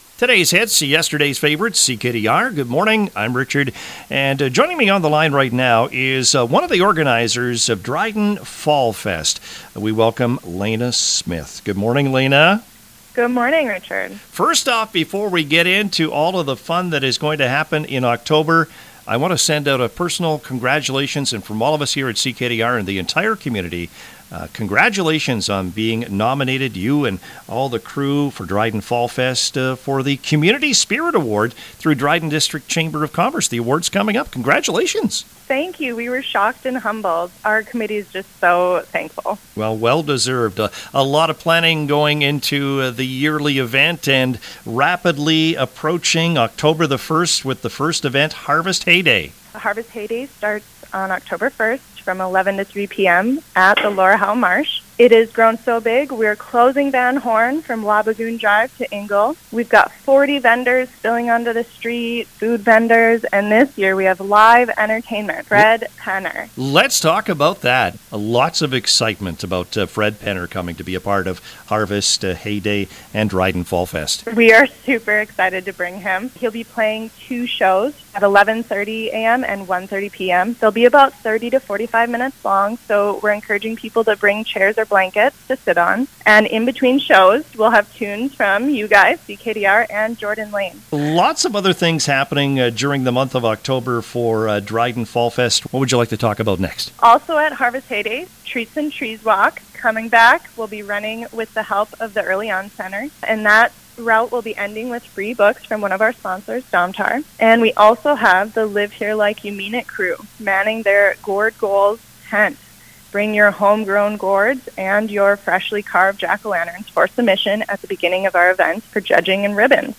she was a guest on the CKDR Morning Show Thursday to talk about the opening event, Harvest Hay Day on the first.